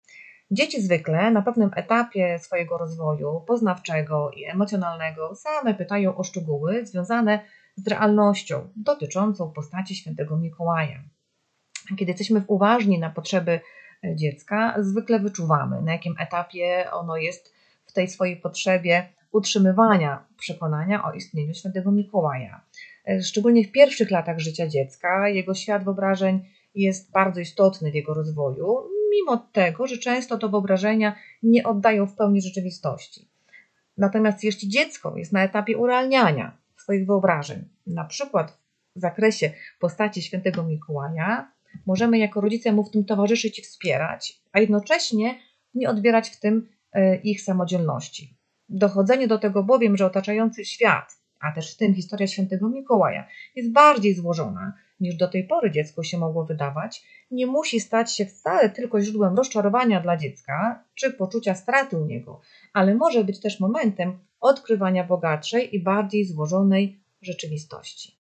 Rozmowa z psychologiem